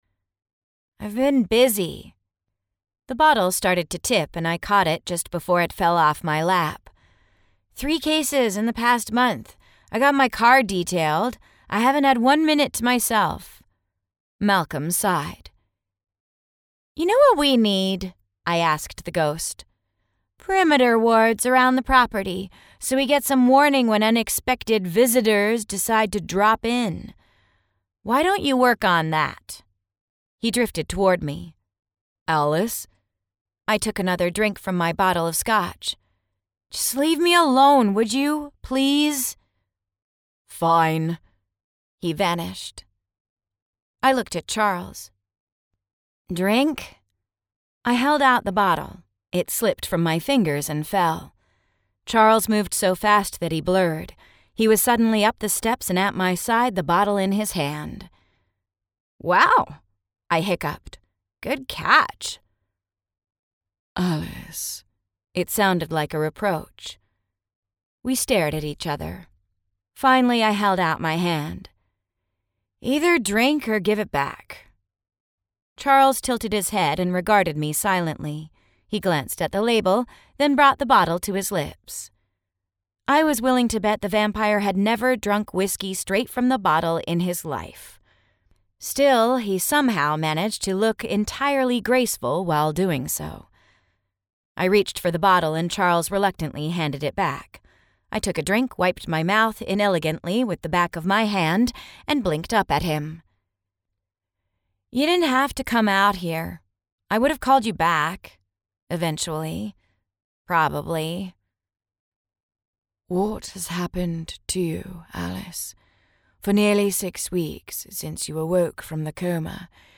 • Audiobook
Heart-of-Fire---Alice-Worth-Book-2-Sample.mp3